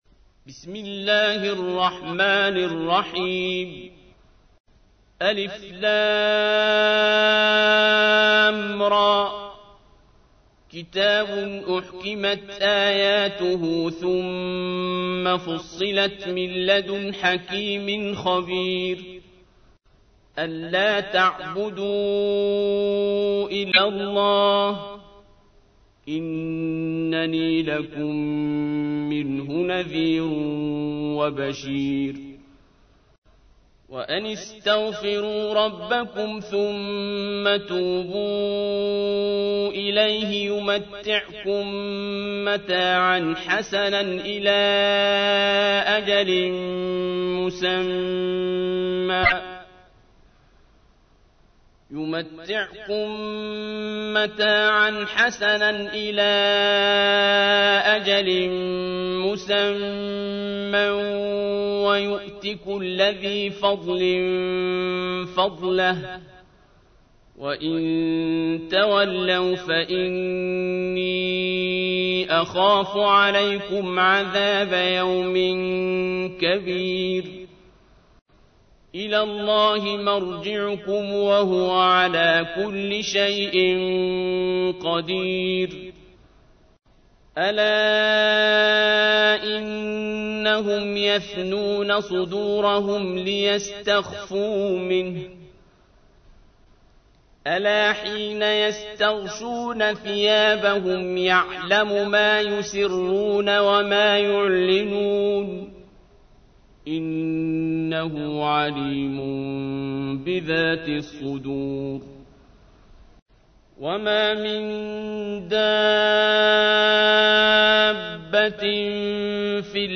تحميل : 11. سورة هود / القارئ عبد الباسط عبد الصمد / القرآن الكريم / موقع يا حسين